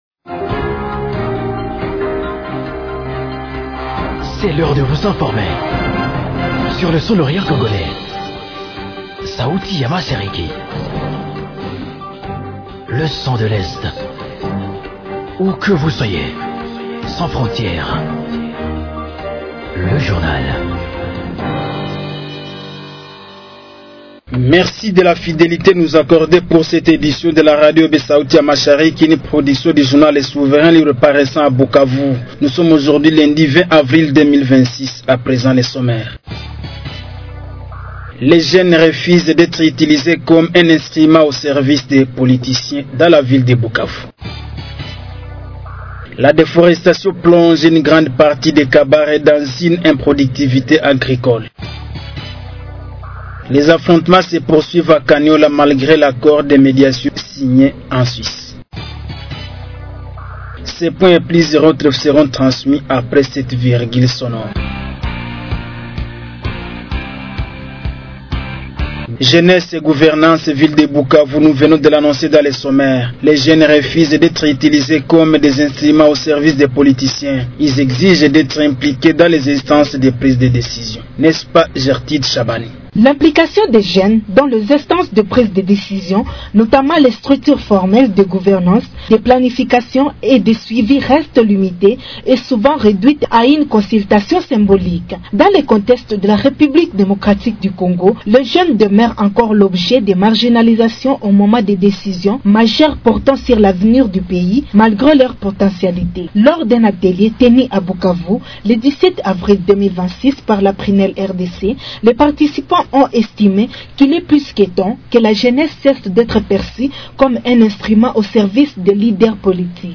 Journal du 20.04.2026